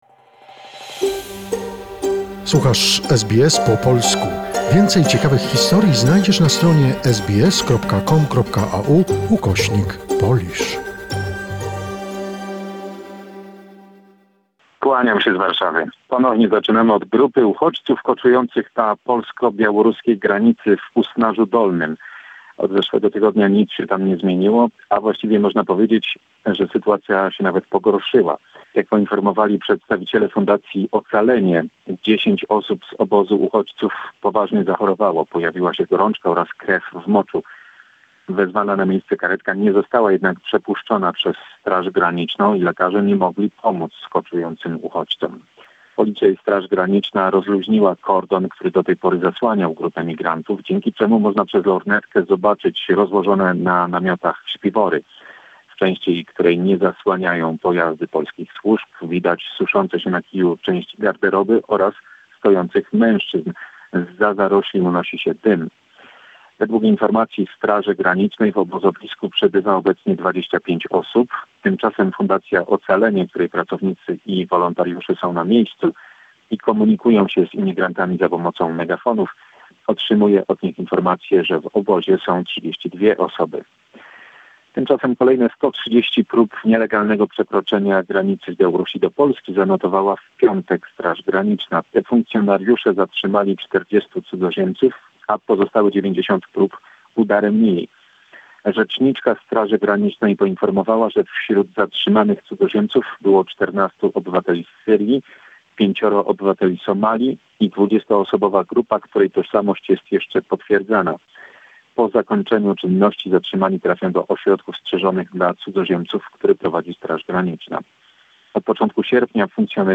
Summary of important events of last week in Poland; report